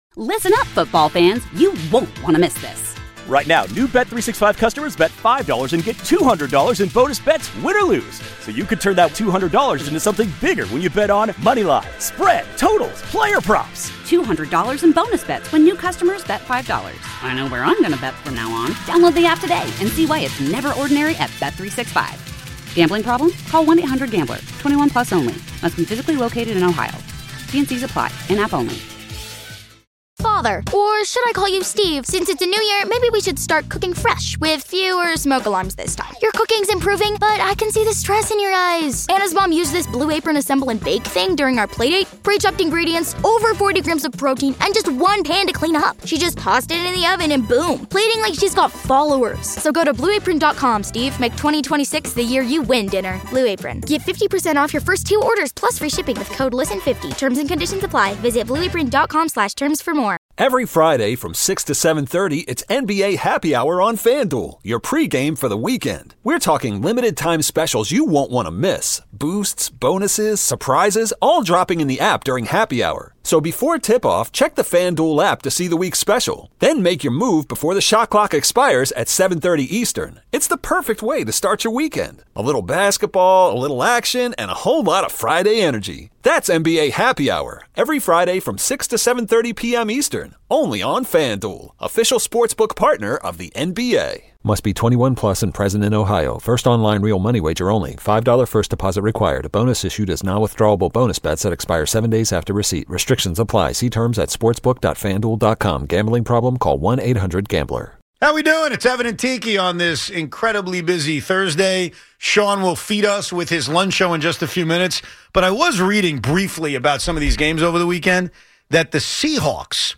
A full-on Giants history lesson with strong opinions, laughs, and classic sports radio chaos.